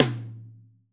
TOM 2H    -L.wav